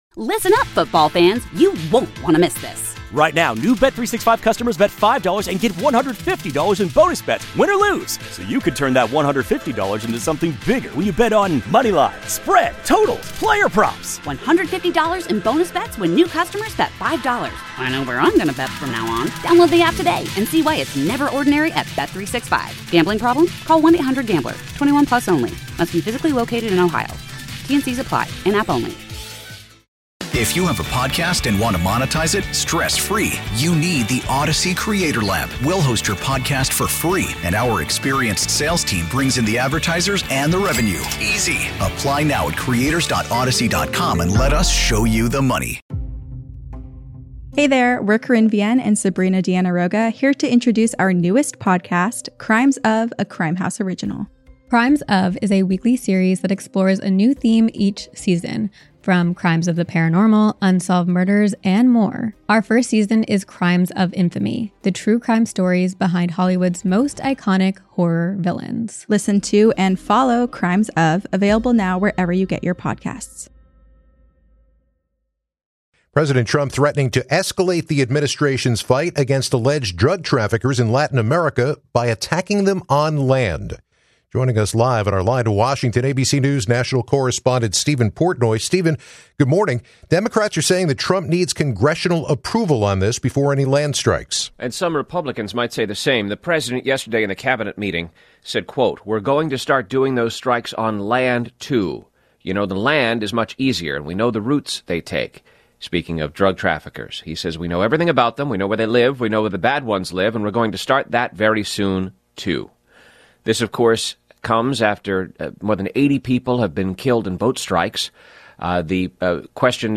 Joining us live on our Line from Washington